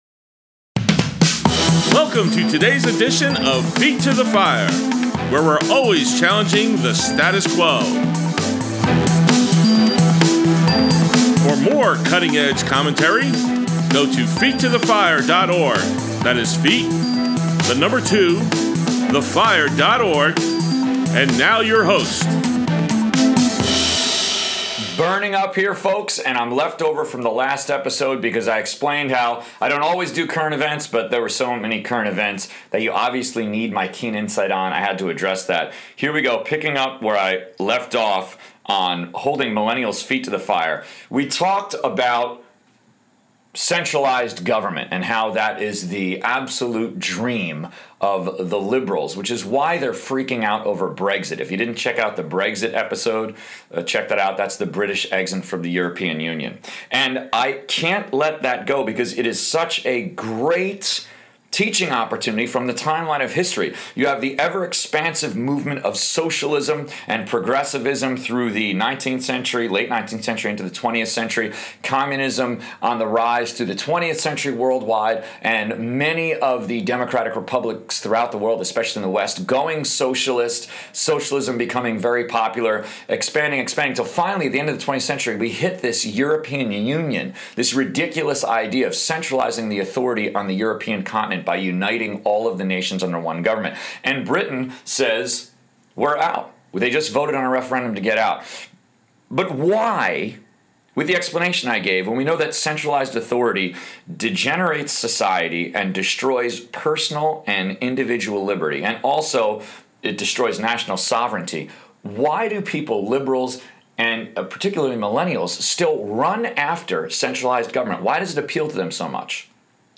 7/11/16 Ep. 61 Why Socialism Is Fashionable & Trendy For Liberals & Millennials | Feet to the Fire Politics: Conservative Talk Show